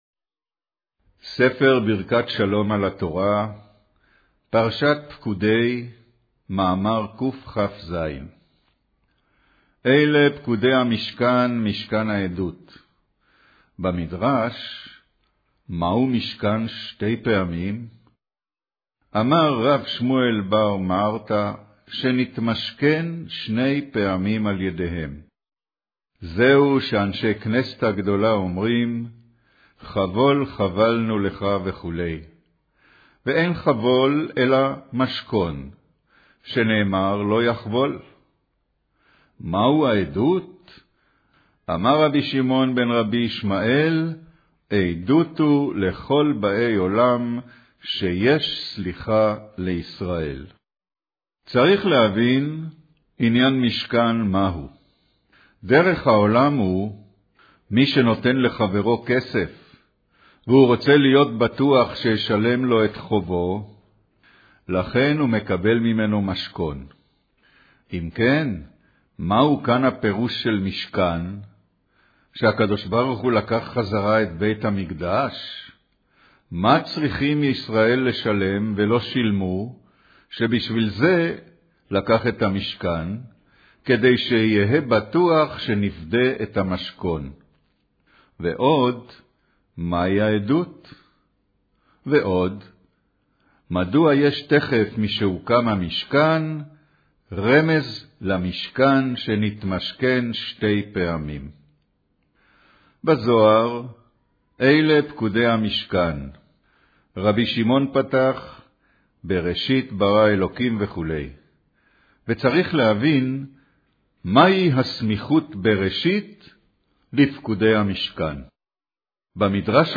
קריינות